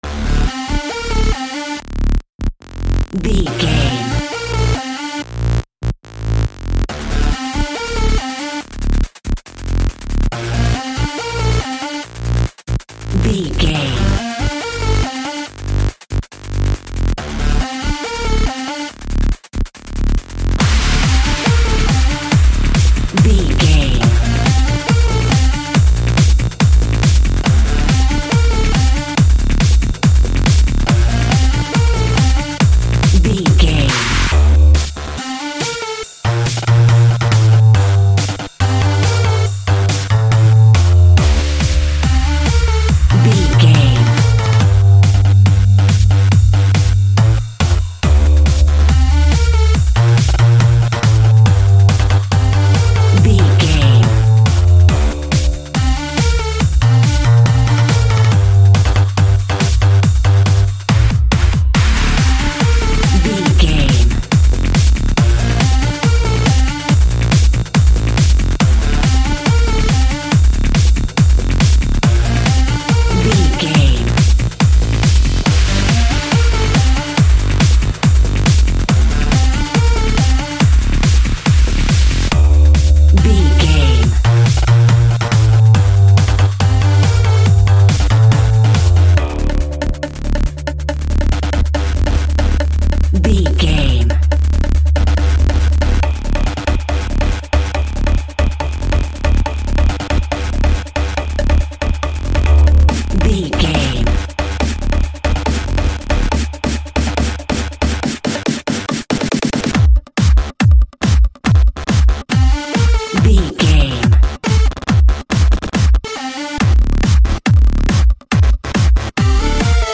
Pulse Bass Dance Music.
Aeolian/Minor
Fast
groovy
futuristic
industrial
hypnotic
drum machine
synthesiser
house
techno
trance
instrumentals
synth leads
synth bass
upbeat